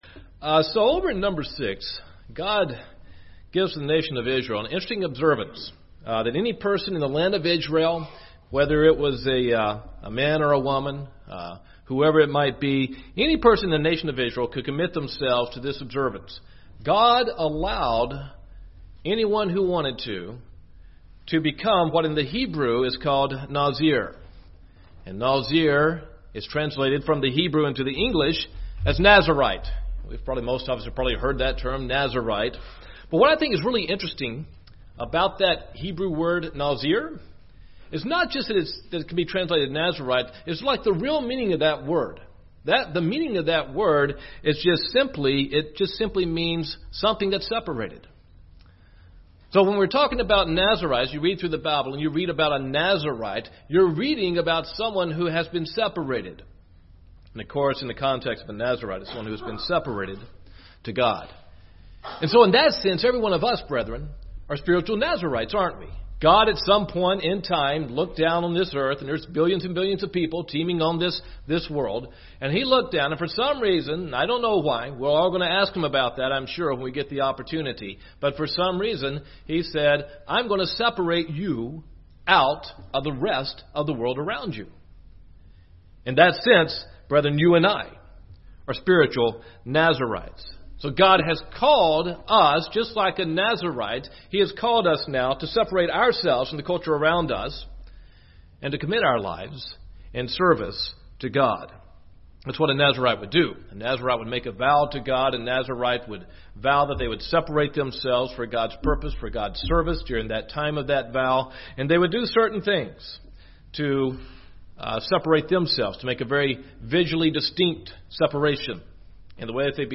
Given in Gadsden, AL
UCG Sermon Studying the bible?